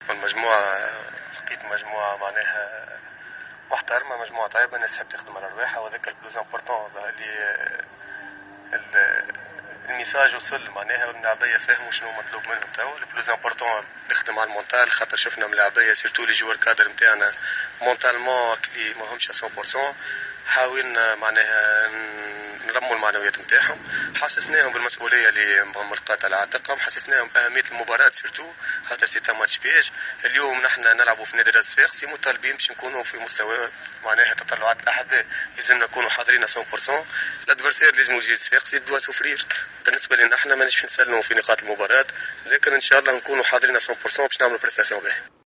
عقدت مساء اليوم إدارة النادي الصفاقسي ندوة صحفية لتقديم الاطار الفني الجديد والحديث عن استعدادات الفريق للقاء الغد أمام أولمبيك مدنين لحساب الجولة 10 من البطولة الوطنية.